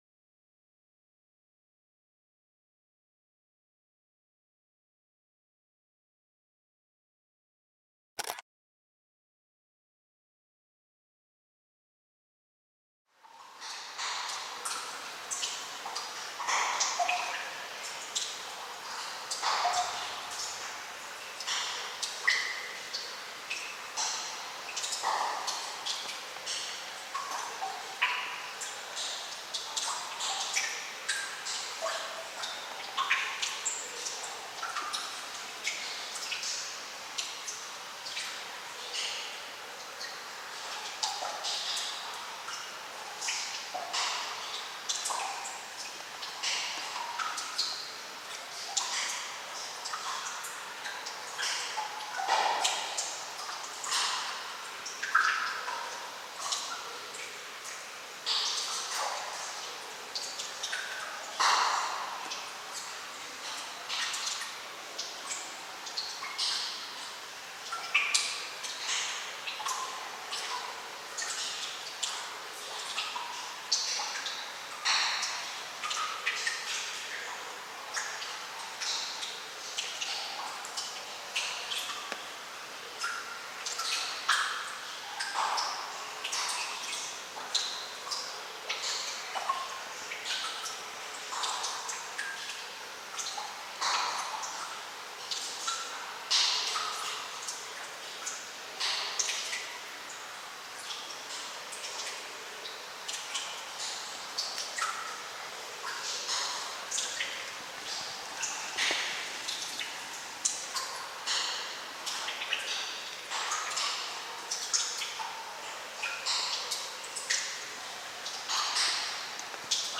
VEREINTE HARMONIE: Vogelklänge-Wasserfall mit Wald-Stimmen